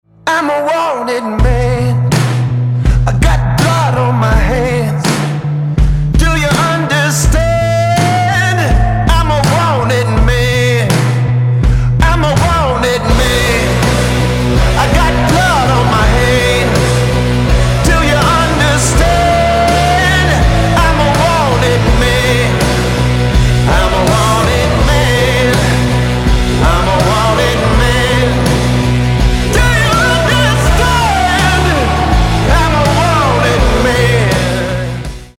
• Качество: 320, Stereo
гитара
ритмичные
мужской вокал
громкие
медленные
качающие
electro